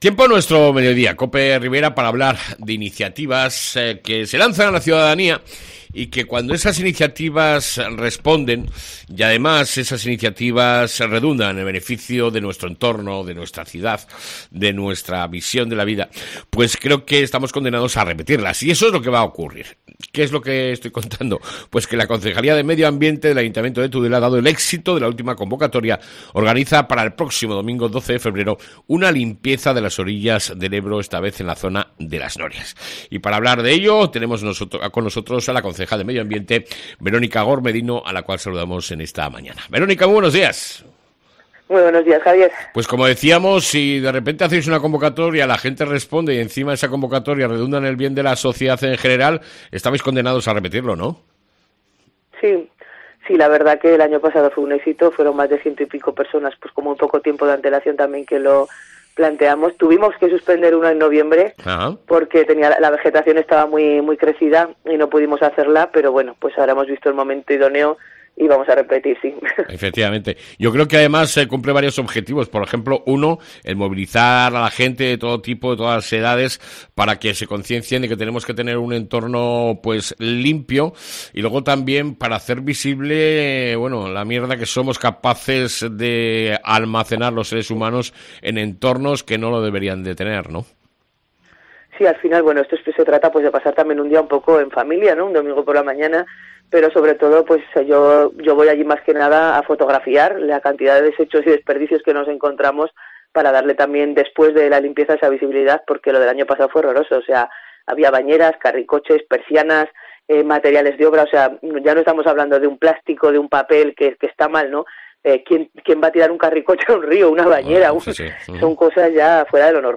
ENTREVISTA CON LA CONCEJAL DE MEDIO AMBIENTE , VERONICA GORMEDINO